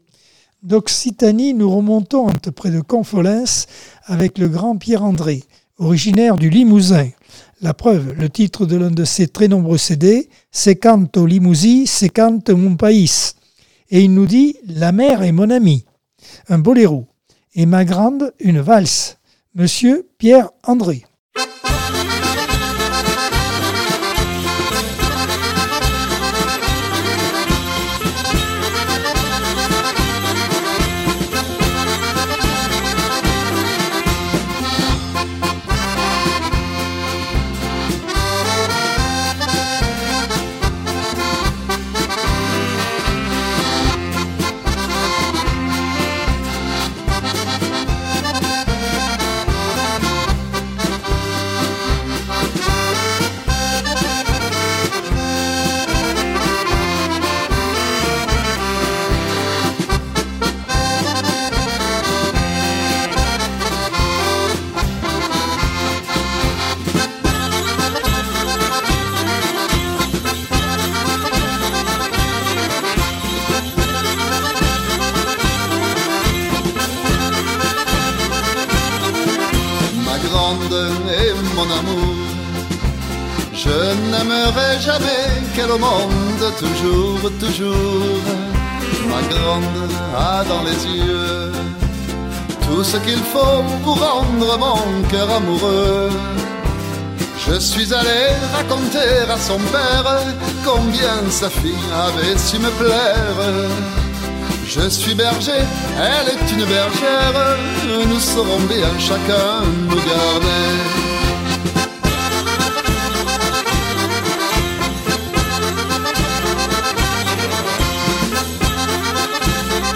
Jeudi par Accordeon 2023 sem 27 bloc 6.